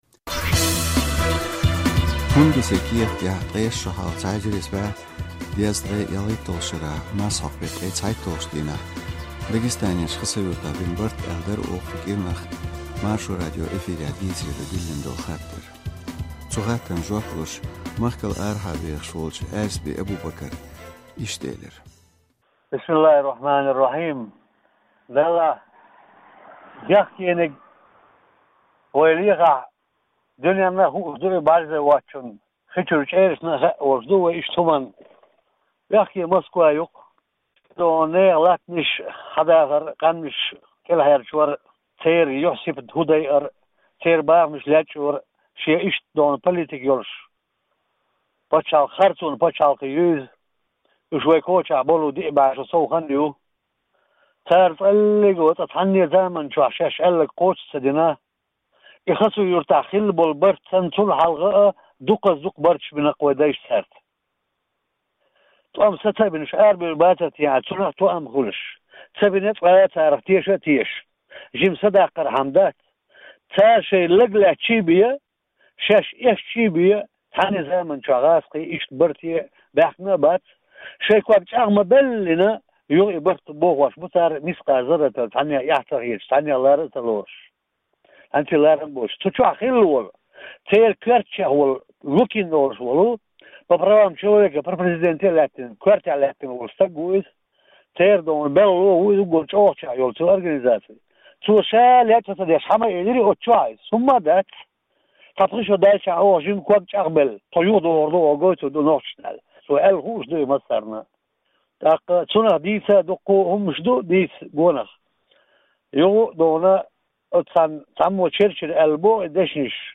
ЛадогIархоша делла жоьпаш хаза аьтто бу шун кхузахь.